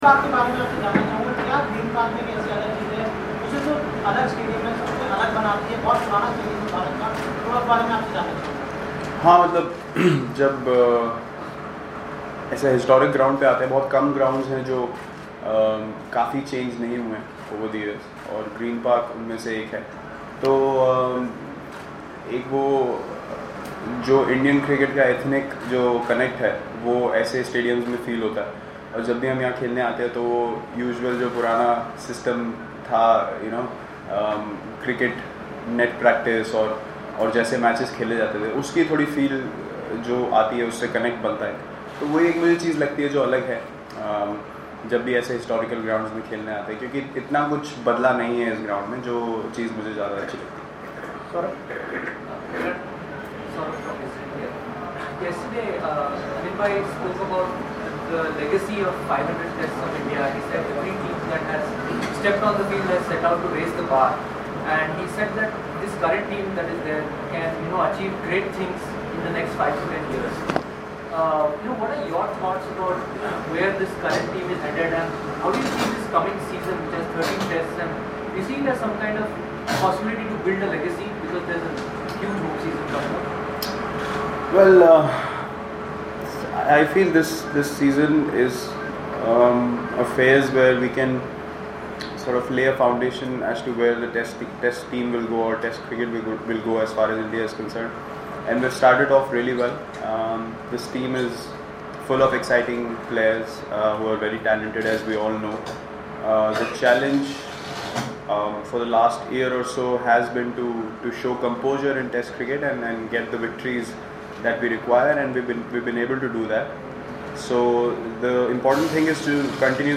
Virat Kohli, Captain, Indian Cricket Team interacts with the media at Green Park Stadium, Kanpur, on Wednesday ahead of the upcoming Test.